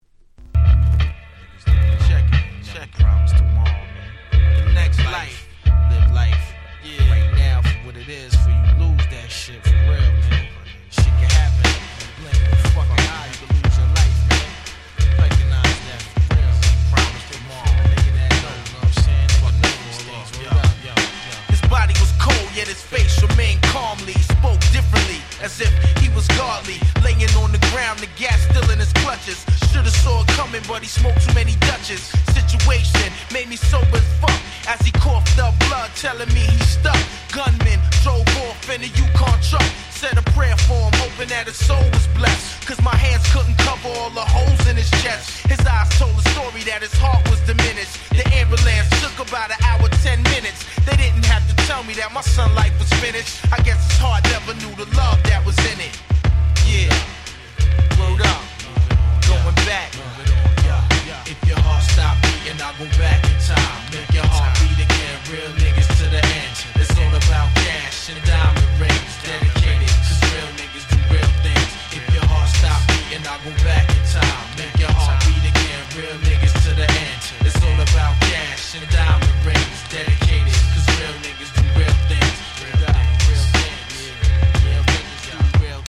ピートロック Boom Bap ブーンバップ